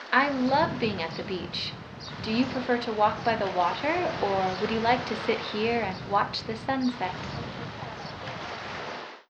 Wind Noise Clip, WITH the Beltone™ M&RIE:
BT_IMG_Audio-File_Wind-Noise_with-MRIE.wav